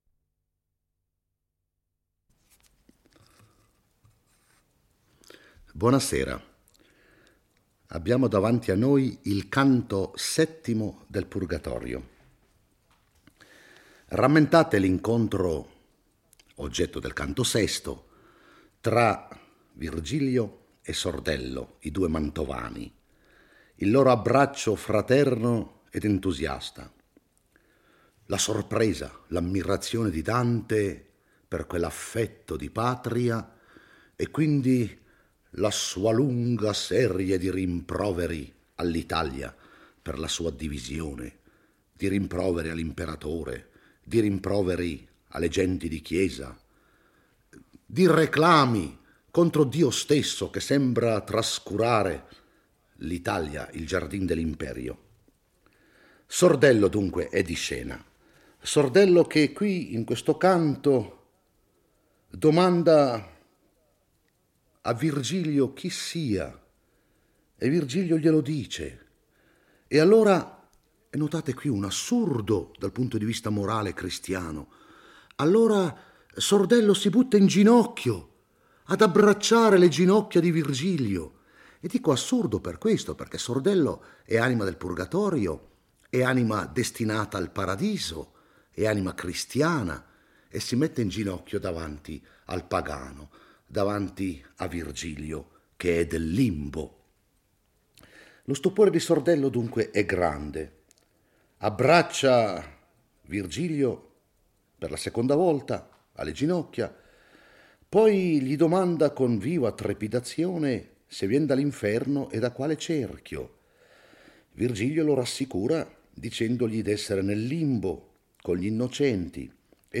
legge e commenta il VII canto del Purgatorio. Dante e Virgilio spiegano a Sordello il motivo del loro viaggio su volontà divina, il quale promette di accompagnarli finché gli è consentito.